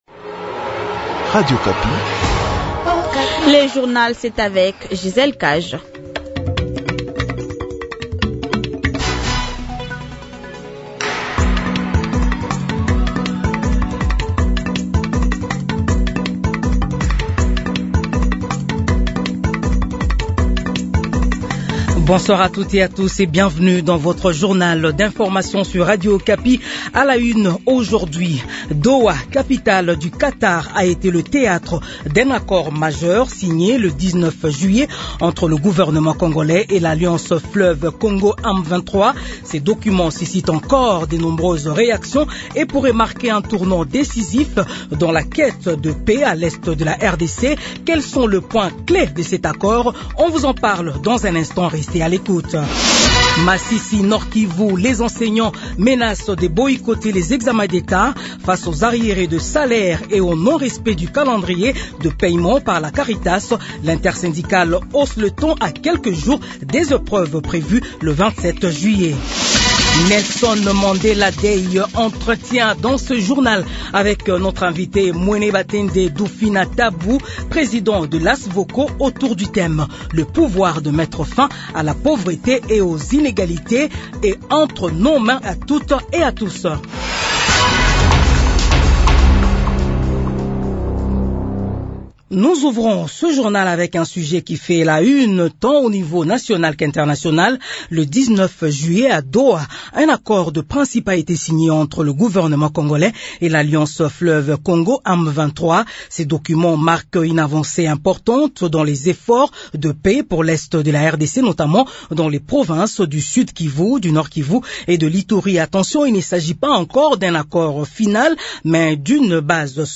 Edition du journal de 18 heures du 20 juillet 2025